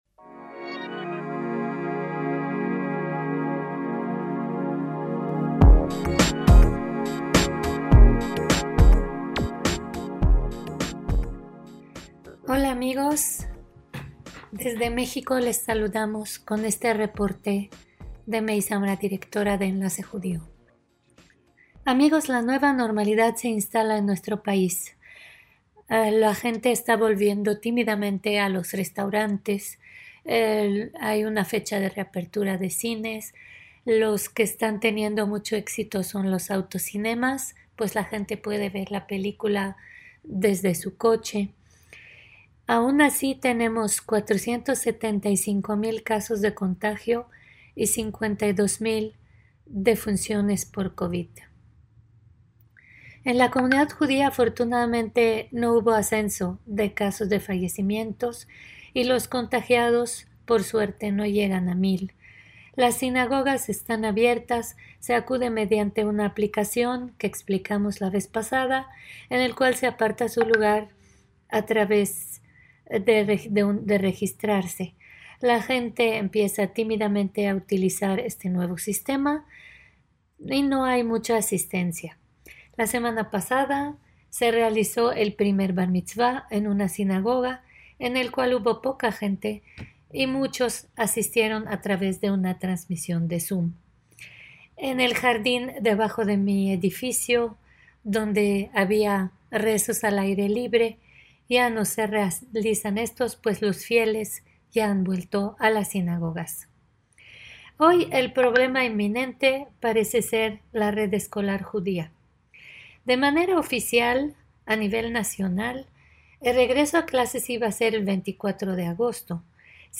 DESDE MÉXICO, CON ENLACE JUDÍO - Desde México nos llega el reporte